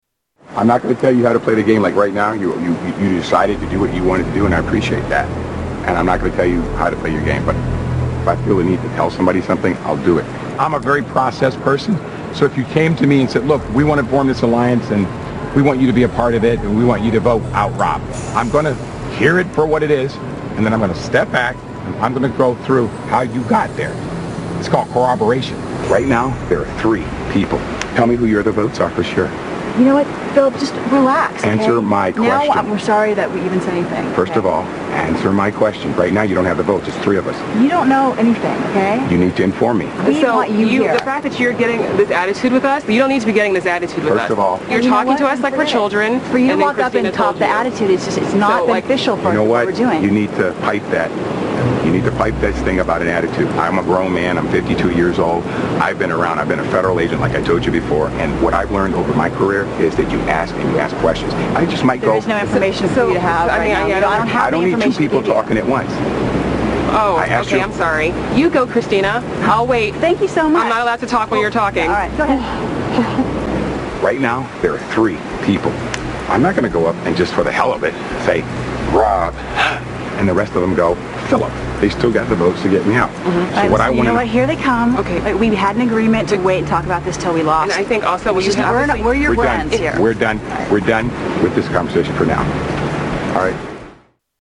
Conversation
Category: Television   Right: Personal